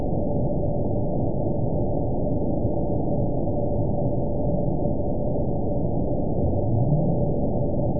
event 922757 date 03/26/25 time 02:44:19 GMT (1 month ago) score 9.31 location TSS-AB01 detected by nrw target species NRW annotations +NRW Spectrogram: Frequency (kHz) vs. Time (s) audio not available .wav